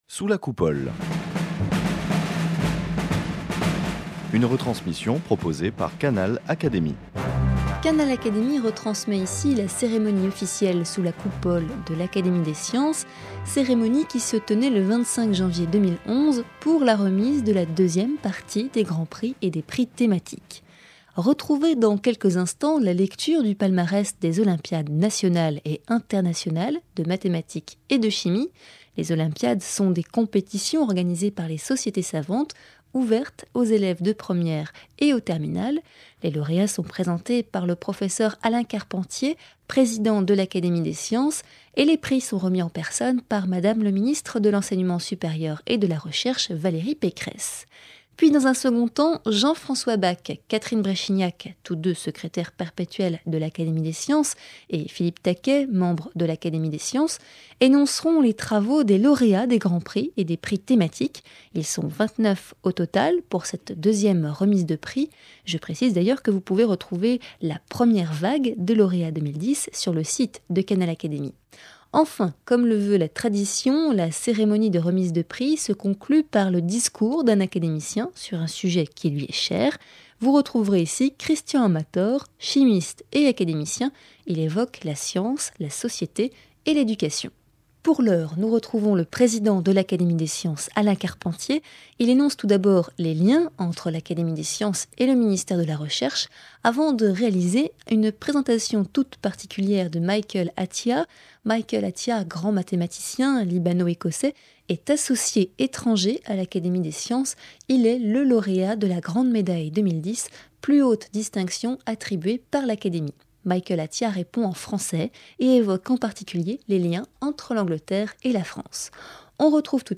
Retransmission intégrale de la seconde séance solennelle de remise des grands Prix et des prix thématiques 2010.